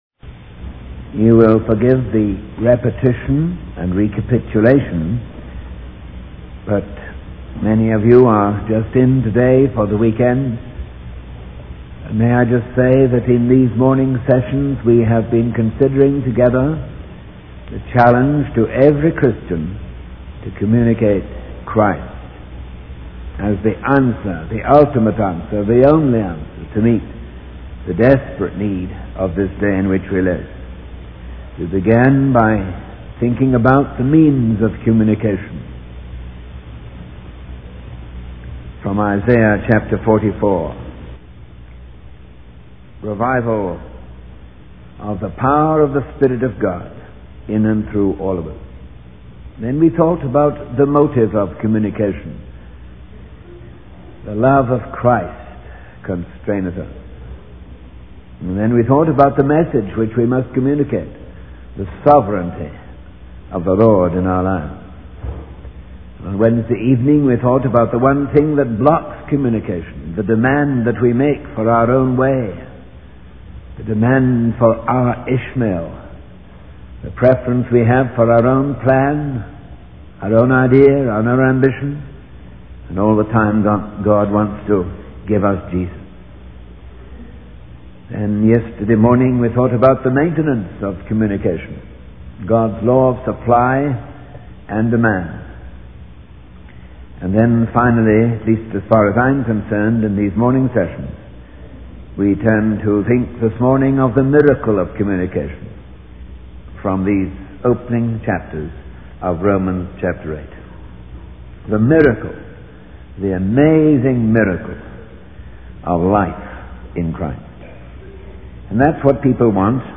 In this sermon, the speaker emphasizes the importance of Christians communicating Christ as the ultimate answer to the desperate needs of the world. They discuss the means of communication, which involves the revival of the power of the Spirit of God in and through believers.